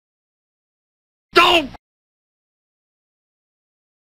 D'OH Homer Simpson 3 Sound
meme